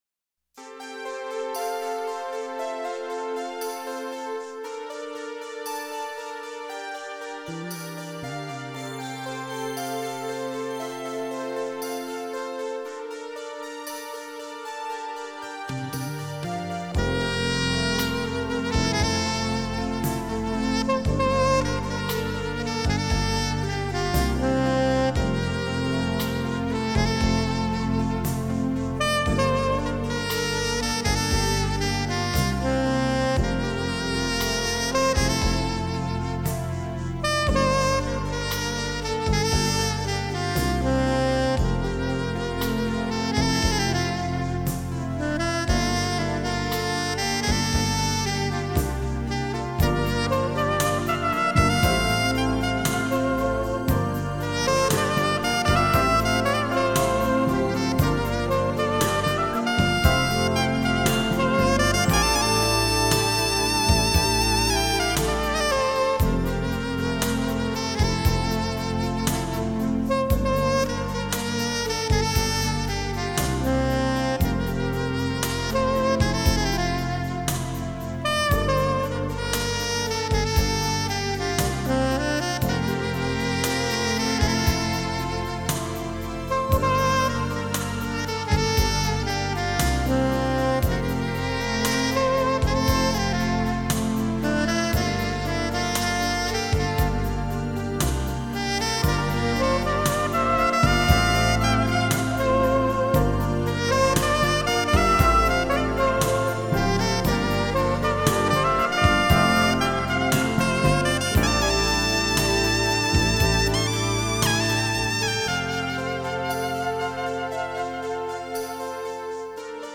Genre: Instrumental.